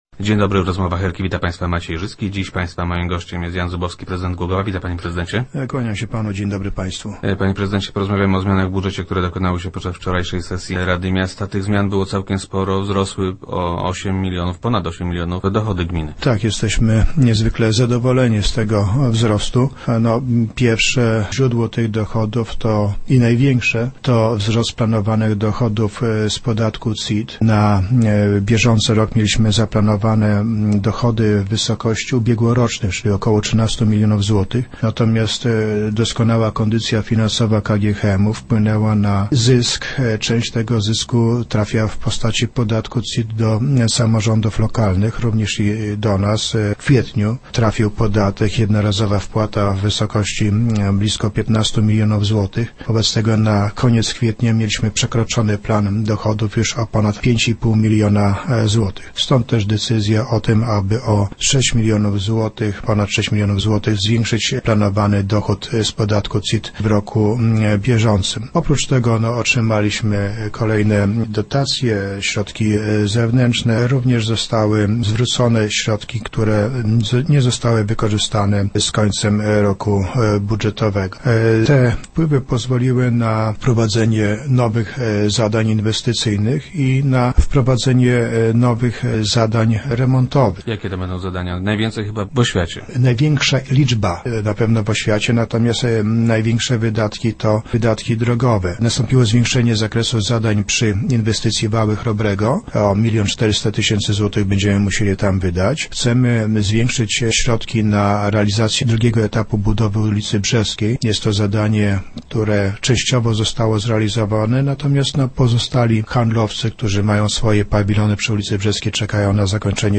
- Doskonała kondycja Polskiej Miedzi wpłynęła na zysk spółki. Część tego zysku, w formie podatku CIT, trafia do samorządów. Również do nas. W kwietniu trafił on do naszego budżetu. Była to jednorazowa wpłata w wysokości blisko piętnastu milionów złotych. Pod koniec kwietnia plan dochodów mieliśmy już przekroczony o ponad pięć i pół miliona złotych. Stąd też decyzja, żeby o sześć milionów zwiększyć planowany dochód z tytułu podatku CIT - mówił prezydent Zubowski, który był gościem Rozmów Elki.